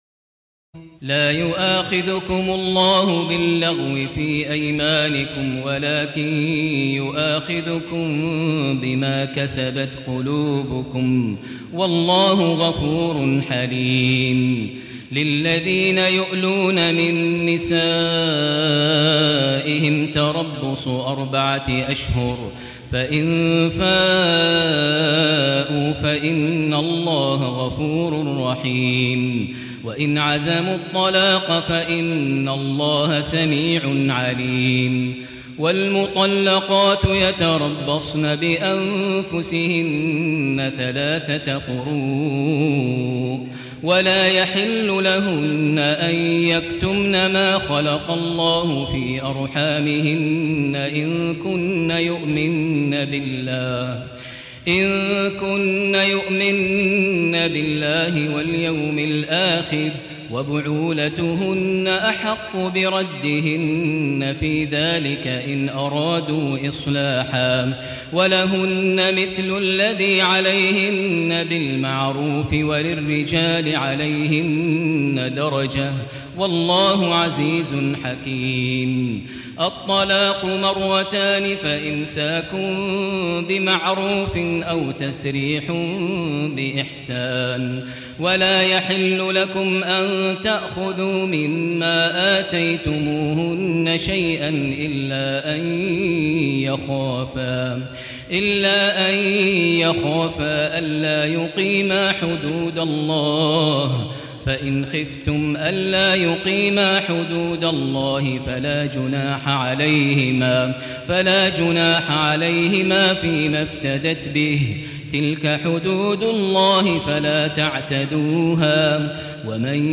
تراويح الليلة الثانية رمضان 1437هـ من سورة البقرة (225-271) Taraweeh 2 st night Ramadan 1437 H from Surah Al-Baqara > تراويح الحرم المكي عام 1437 🕋 > التراويح - تلاوات الحرمين